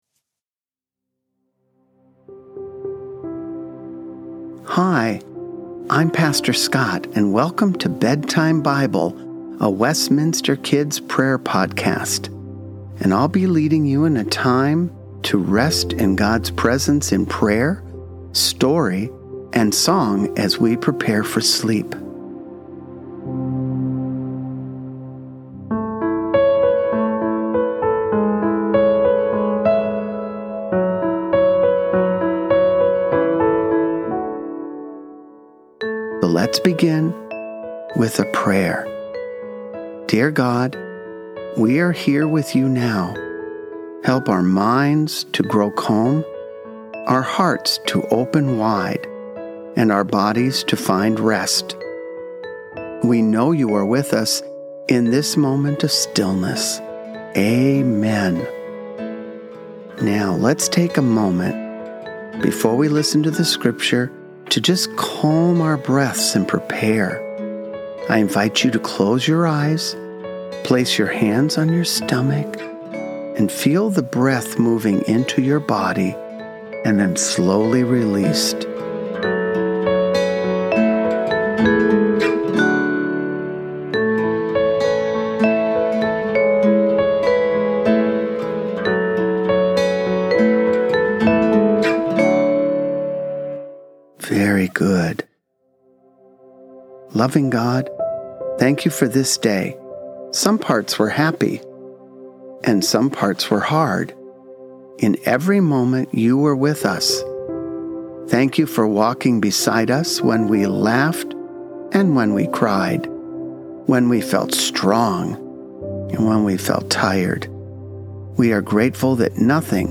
The music is a traditional Irish melody.
Help your children prepare for bed with our prayer and relaxation podcast.  Each podcast will feature calming music, Scripture and prayers to help children unwind from their day.